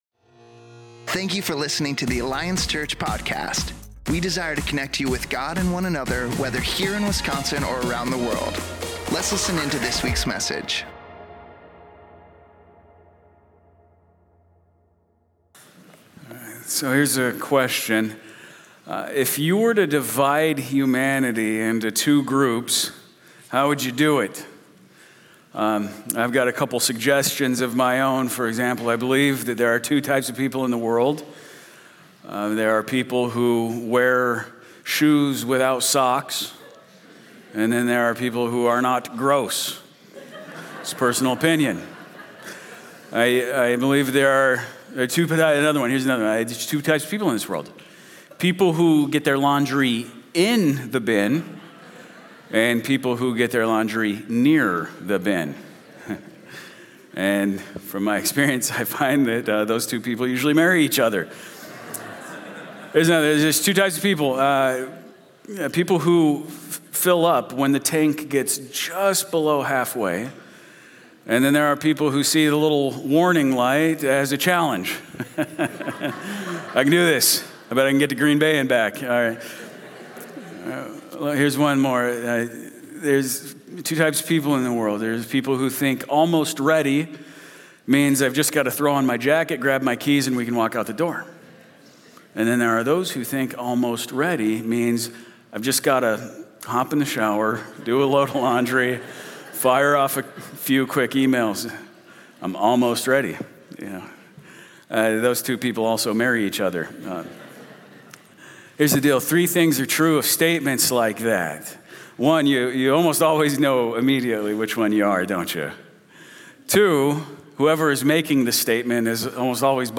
More Sermons People who love Jesus have a desire to live for Him